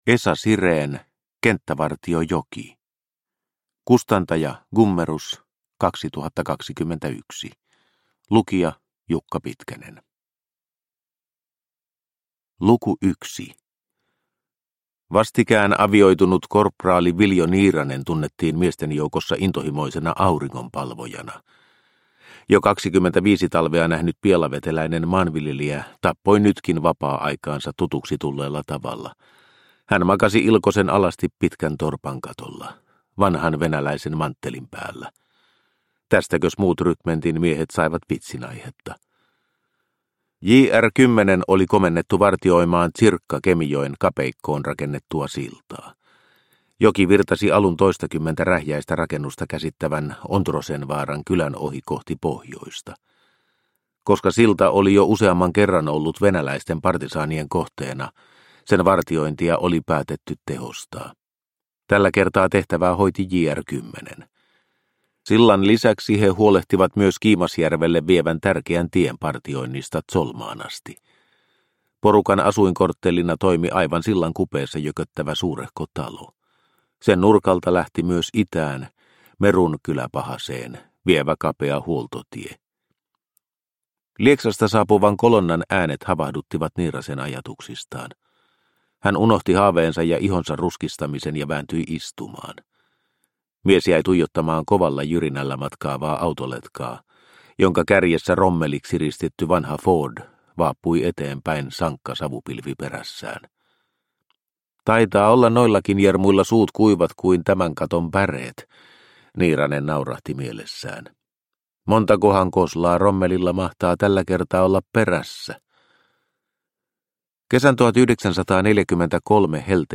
Kenttävartio Joki – Ljudbok – Laddas ner